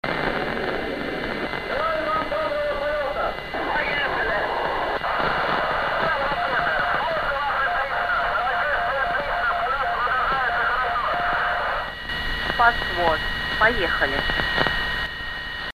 I receive ARISSAT-1 on 145.950 MHZ in FM
You can hear the historical conversation between Yuri Gagrin and the russian ground control.
I am pleased to receive this historical conversation via ARISSAT-1.